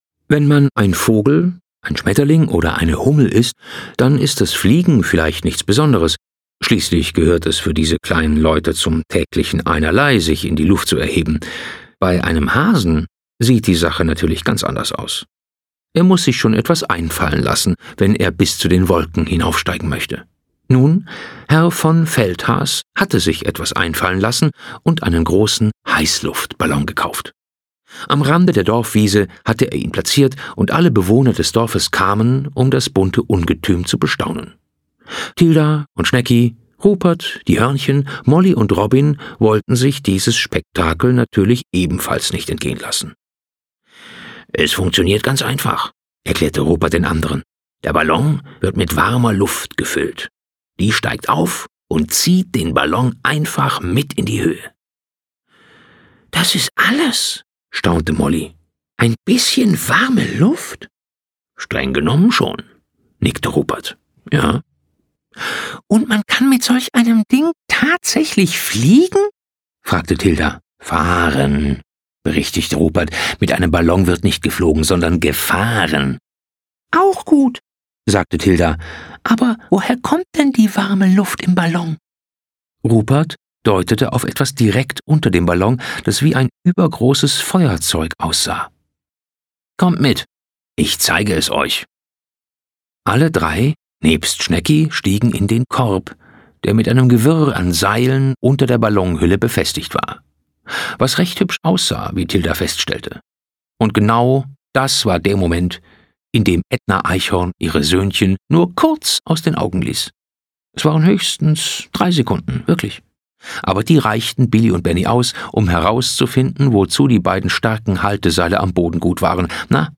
Schlagworte Bilderbücher: Menschen, Figuren, Charaktere • Freundschaft • Heckenrosenweg • Hörbuch; Lesung für Kinder/Jugendliche • Tilda Apfelkern